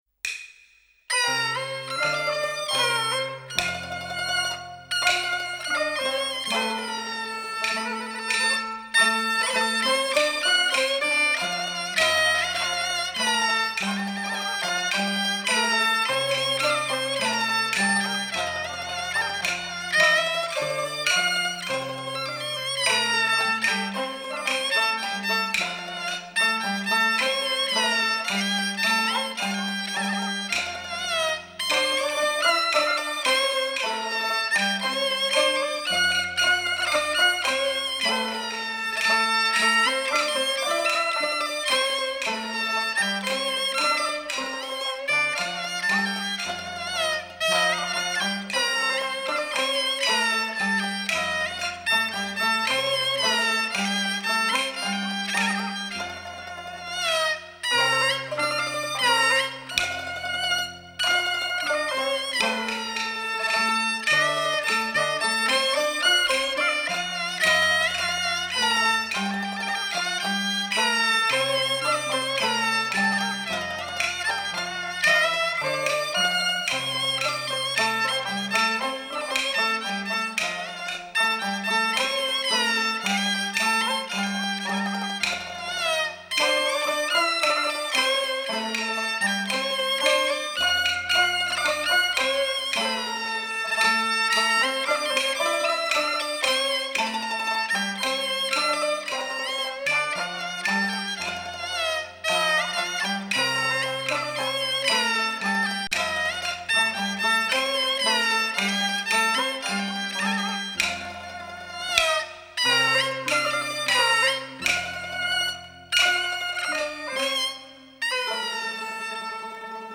0022-京胡名曲寄生草.mp3